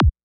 Here You can listen to some of the Kicks included in the sample library:
• Versatile and Dynamic: Whether you're producing trance, psytrance, or any other high-energy electronic music, these kicks will add that essential punch to your tracks.
KICK-VI-210.wav